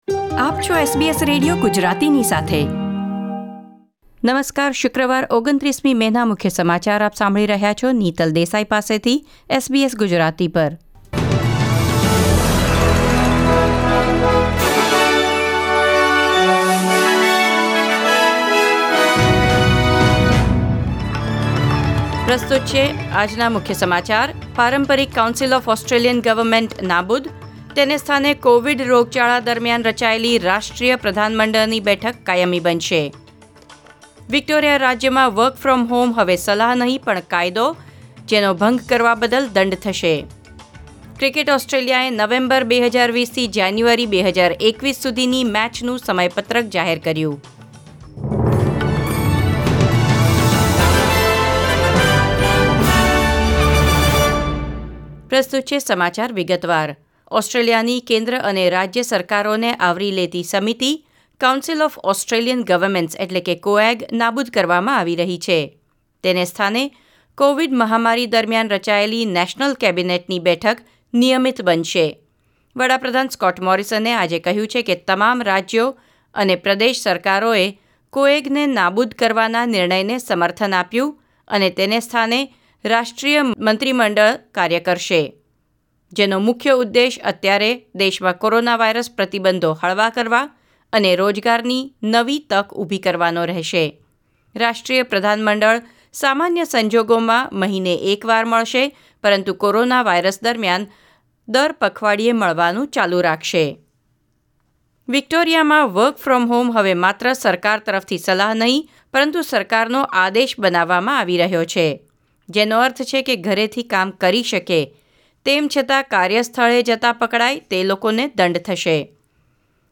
SBS Gujarati News Bulletin 29 May 2020